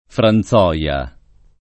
Franzoia [ fran Z0L a ]